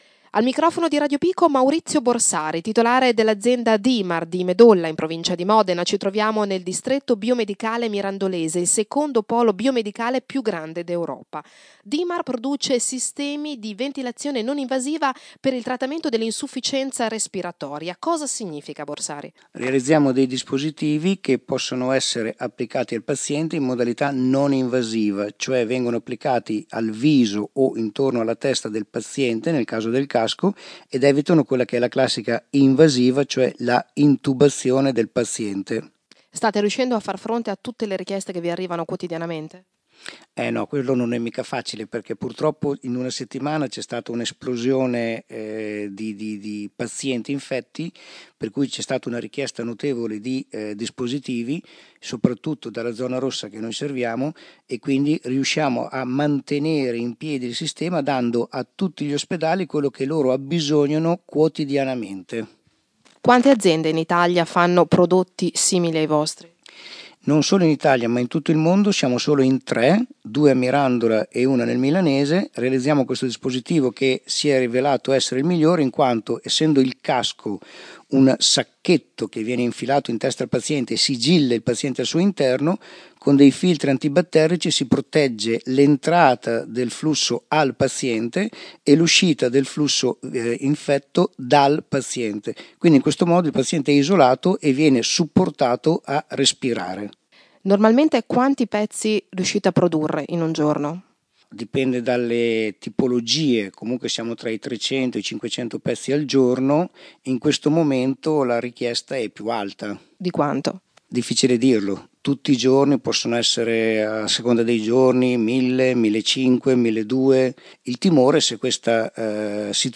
Lo abbiamo intervistato fra una telefonata e l’altra, in un susseguirsi di richieste urgenti di dispositivi.